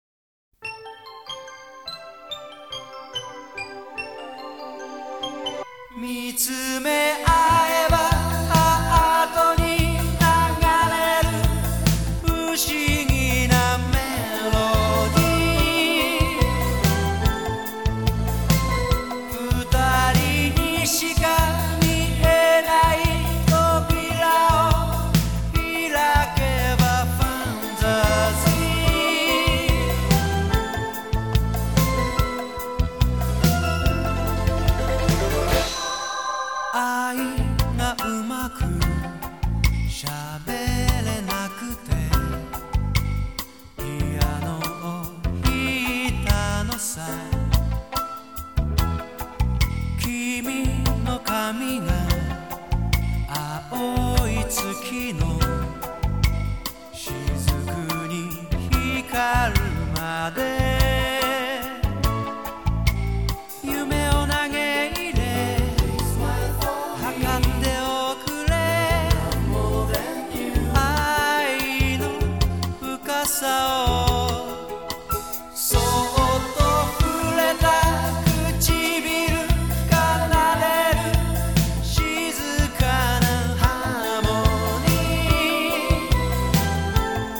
Sigla di chiusura